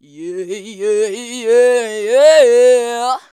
YEAHAYEAH2.wav